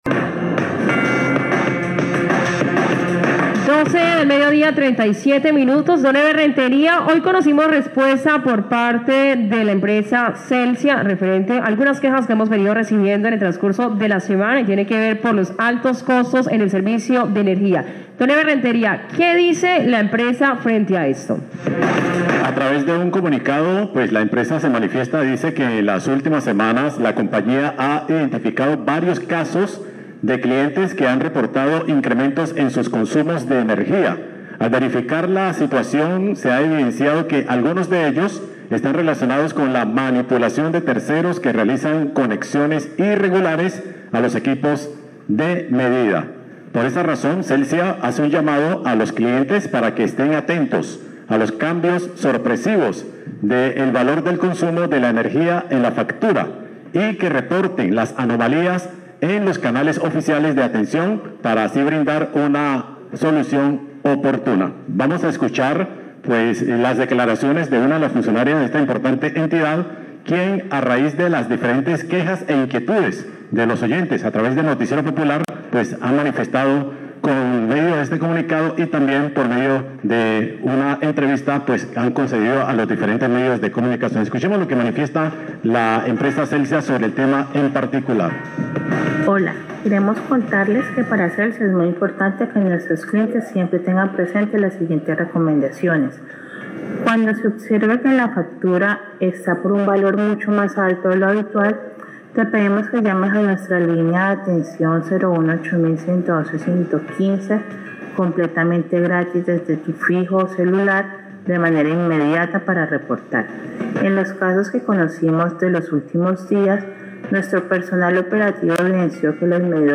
comunicado de prensa